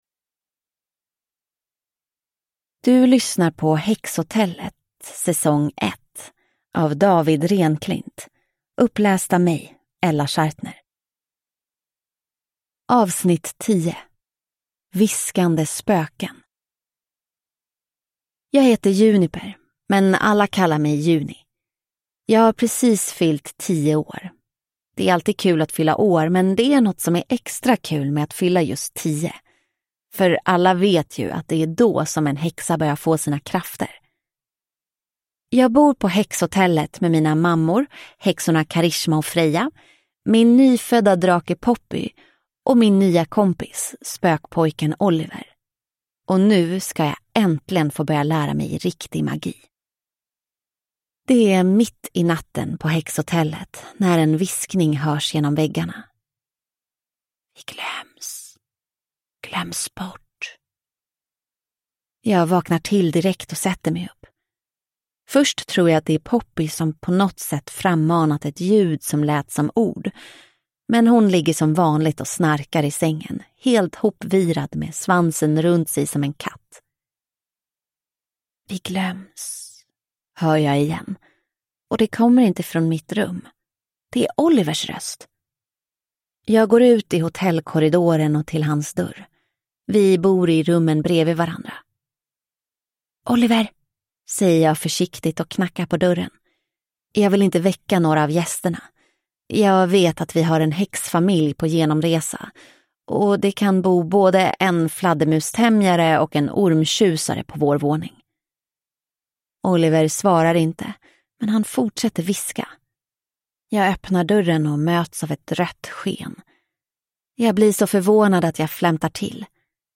Viskande spöken (S1E10 Häxhotellet) – Ljudbok